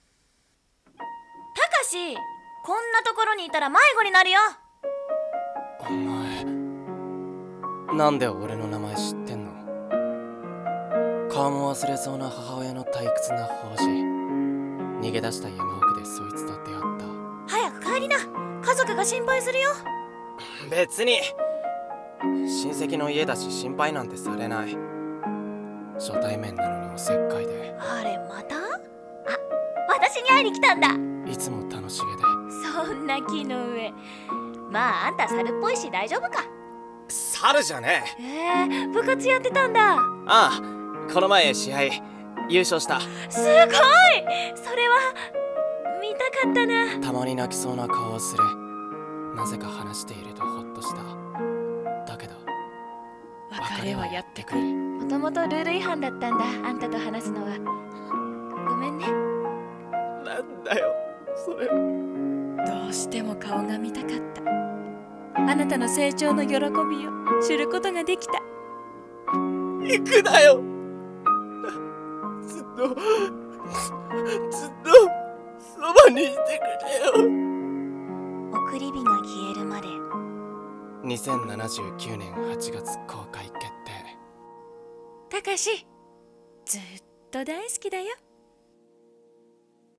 送り火が消えるまで【二人声劇】